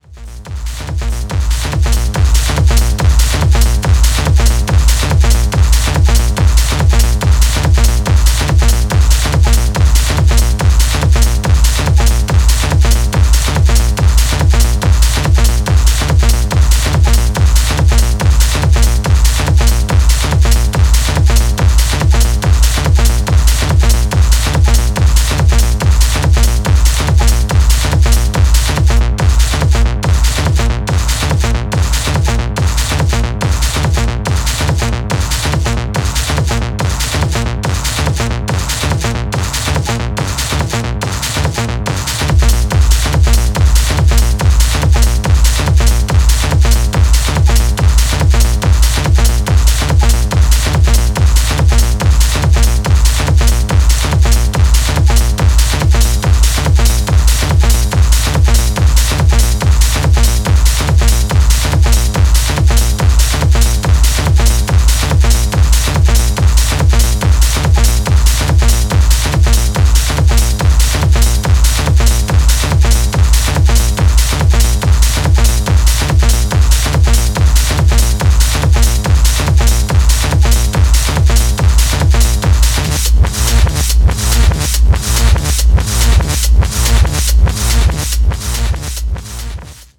Paranoidic pounding techno on a mysterious new imprint.